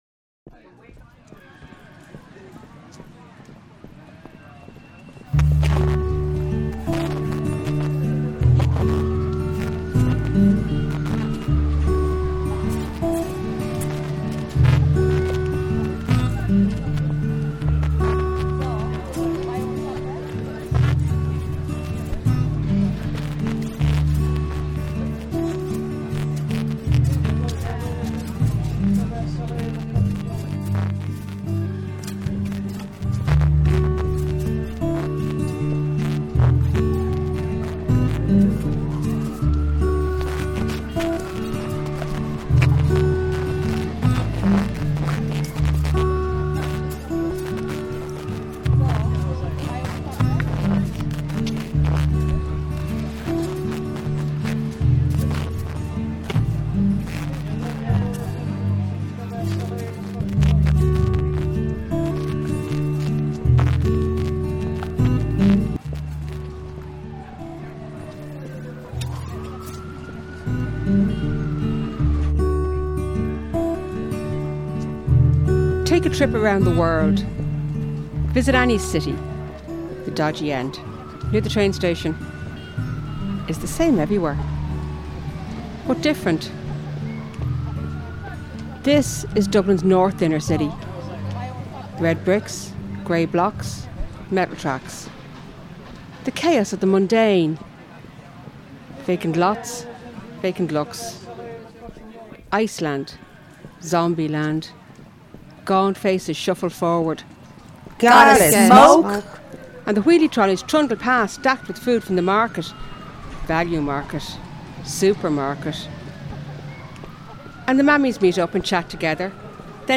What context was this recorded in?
This play was originally performed live in the New Theatre, Dublin, and then recorded as a radio play.